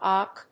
/ək(米国英語)/